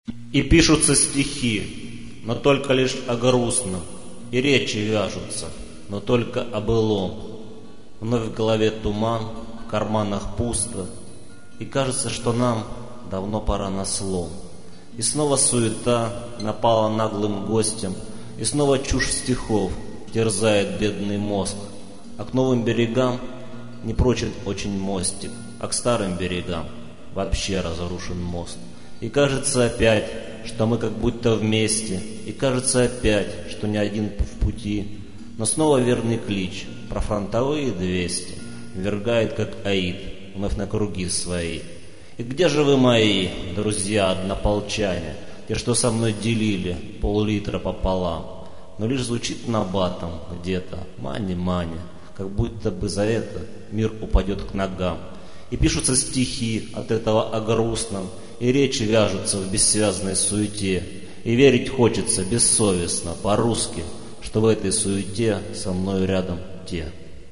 стих